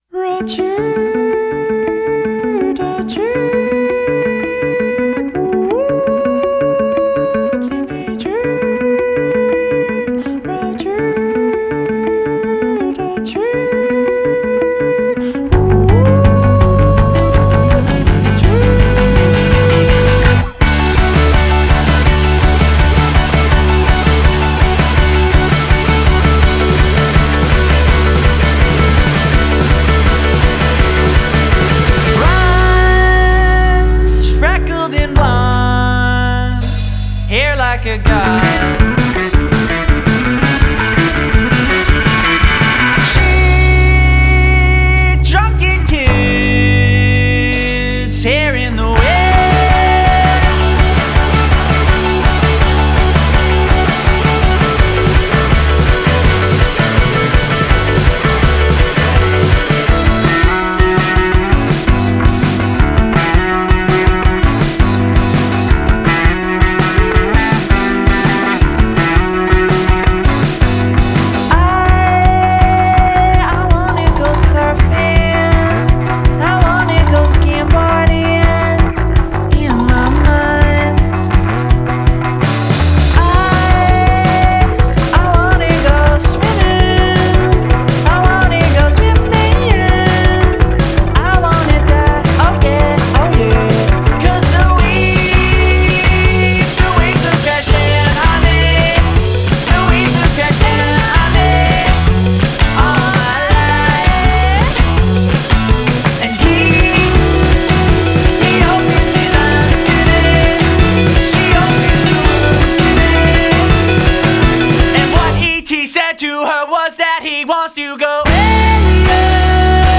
insanely infectious pop music
a more typically upbeat
plenty of youthful energy, and a ton a pop hooks